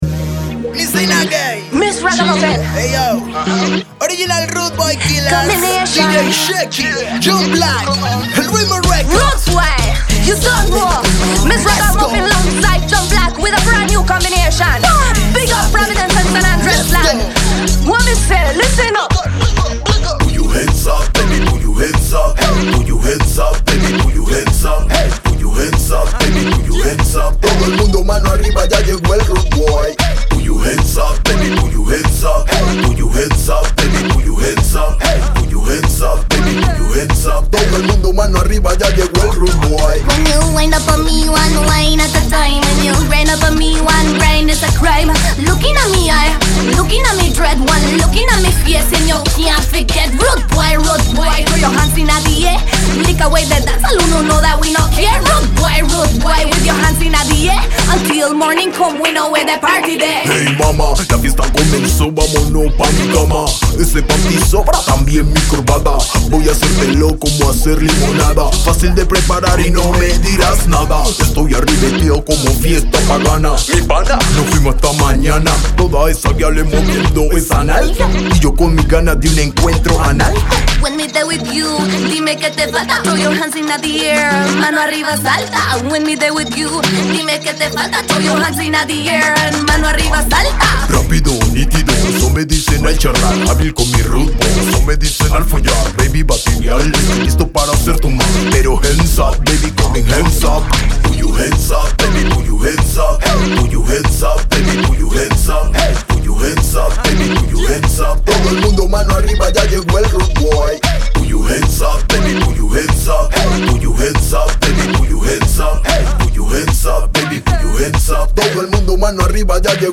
un dancehall bastante alegre, optimo para bailar